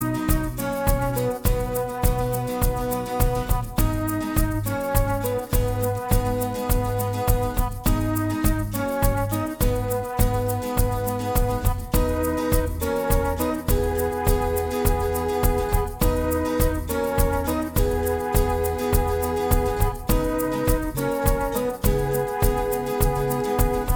Minus Lead Guitars Pop (1980s) 4:21 Buy £1.50